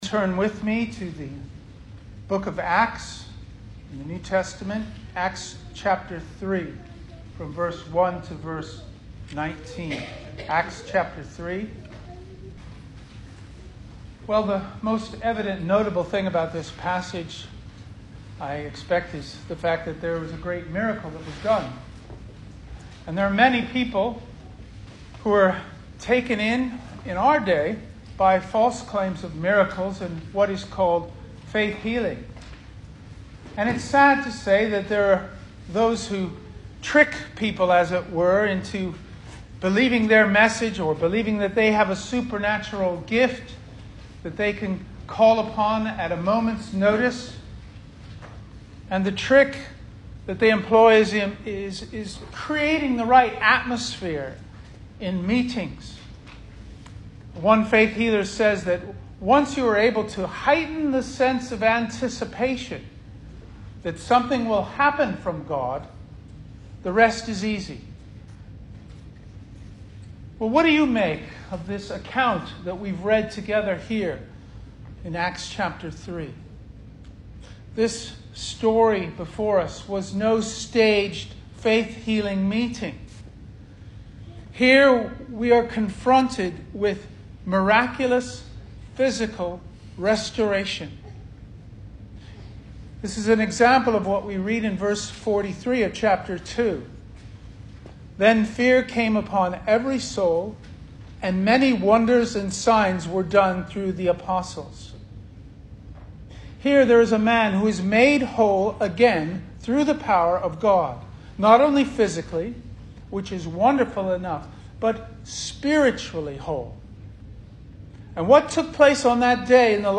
2021 Service Type: Sunday Morning Speaker
Series: Single Sermons Topics: Gospel , Grace